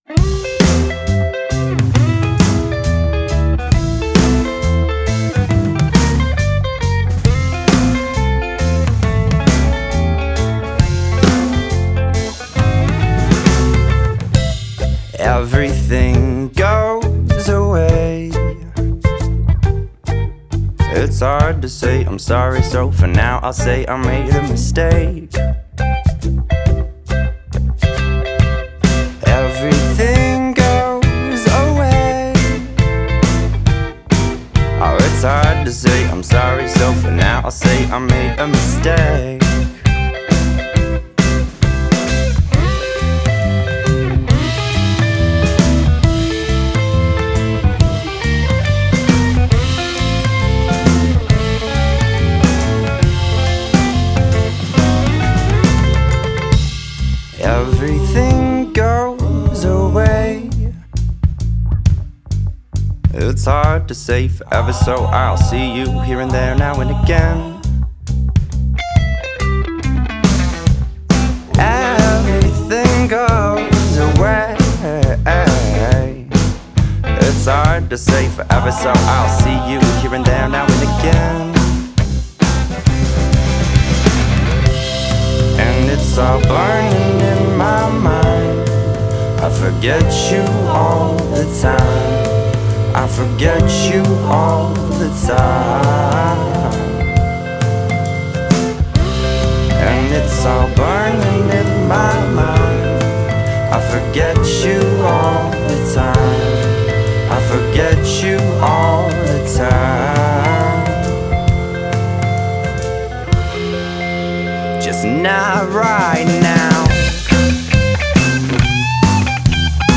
rock/pop/jazz group